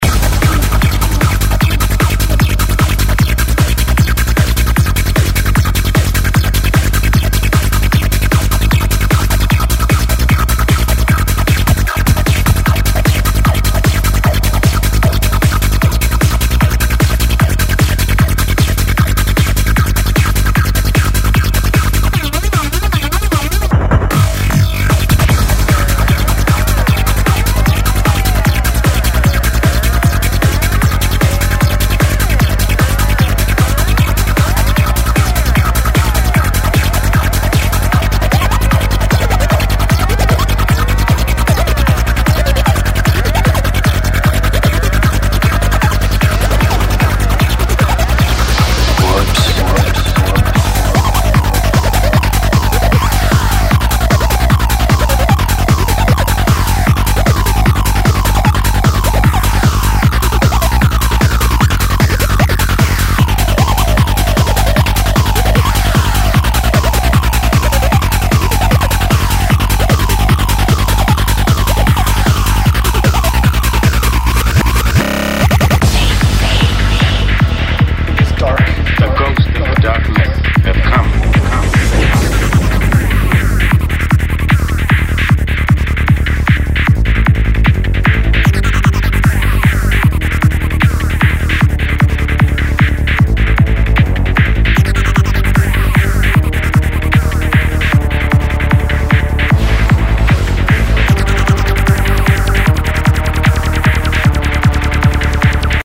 File: Twisted Psychedelic Trance
148 bpm
Hard  full on  without  compromise